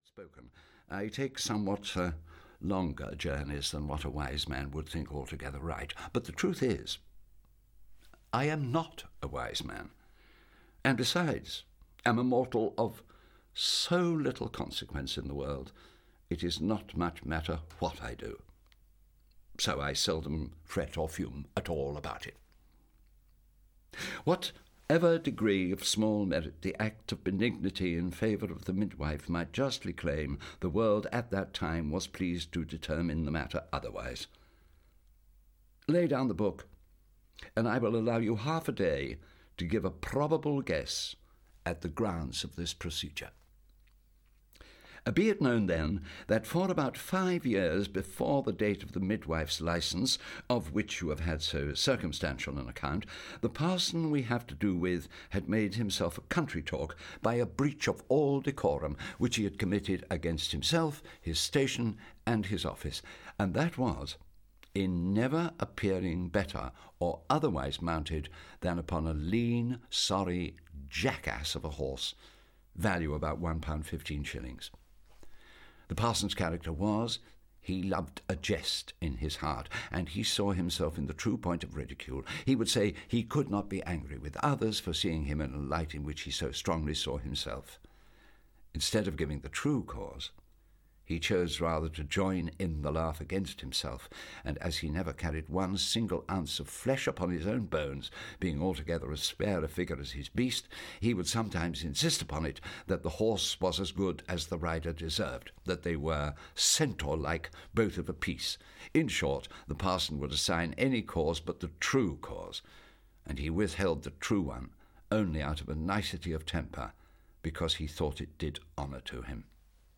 Tristram Shandy - Laurence Sterne - Hörbuch